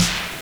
Snare (5).wav